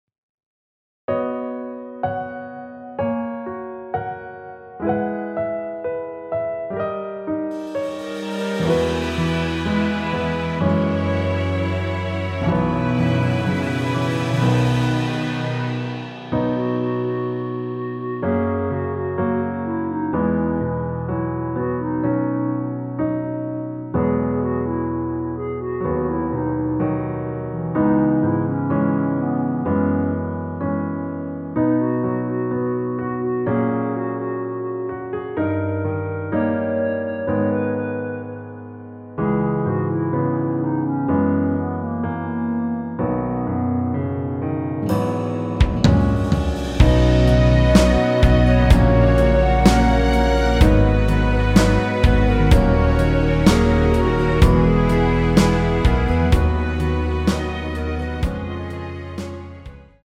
원키에서(-2)내린? (1절앞+후렴)으로 진행되는 멜로디 포함된 MR입니다.(미리듣기 확인)
멜로디 MR이라고 합니다.
앞부분30초, 뒷부분30초씩 편집해서 올려 드리고 있습니다.
중간에 음이 끈어지고 다시 나오는 이유는